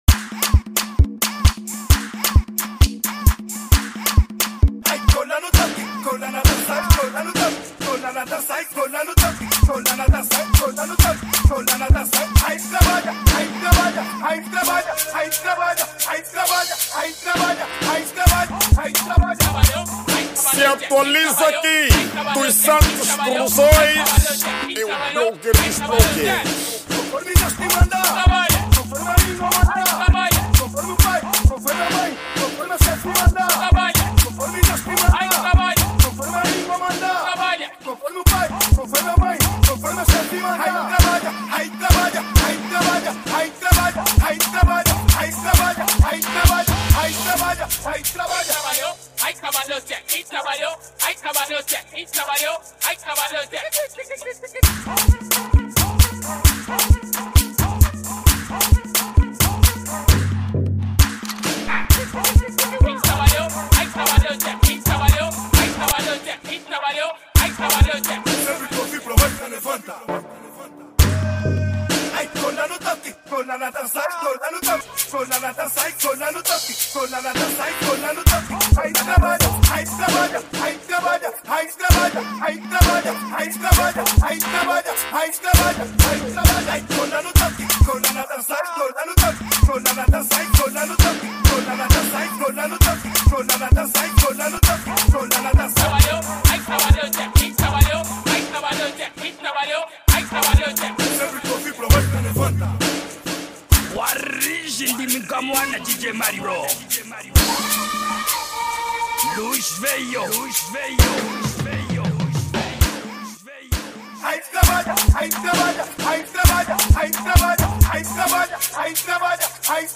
Categoria Afro  House